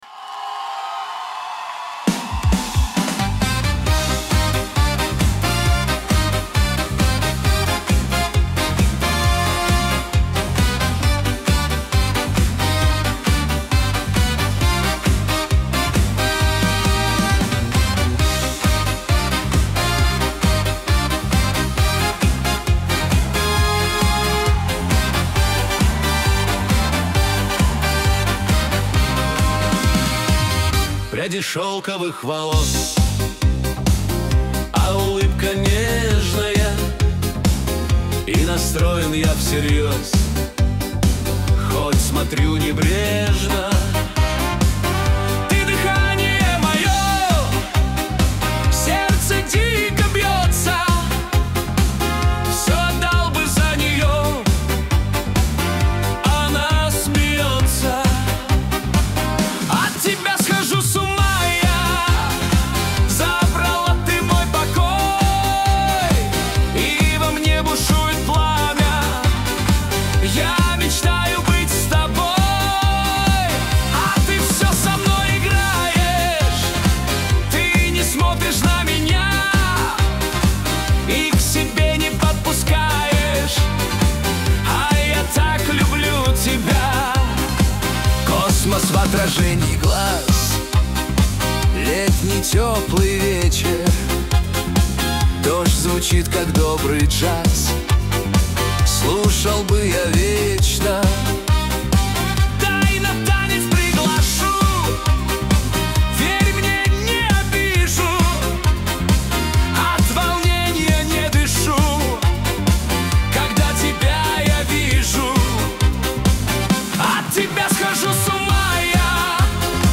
Качество: 320 kbps, stereo
Русские поп песни, Русские треки, 2026